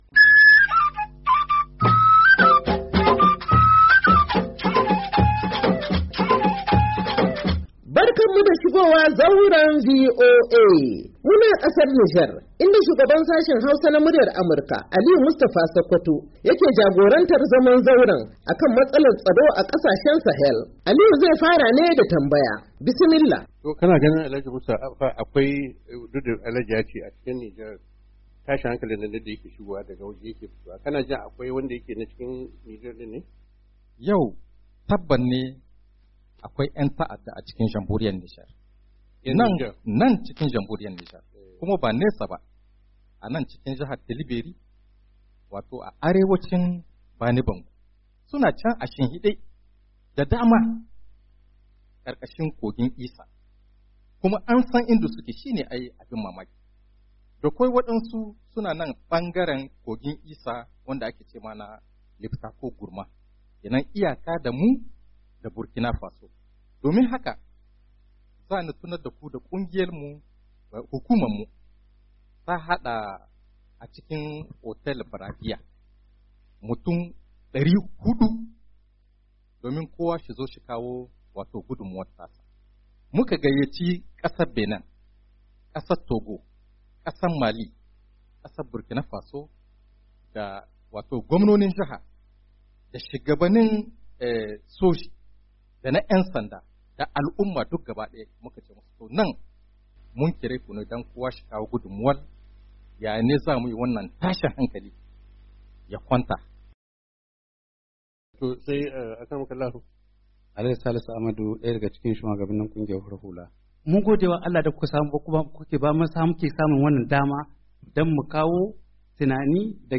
ZAUREN VOA: Hira Mustapha Khadi Wakilin Hukuma Mai Shiga Tsakani Ta Kasar Nijar Akan Rikicin Gida Da Na Waje, Mayu 1, 2022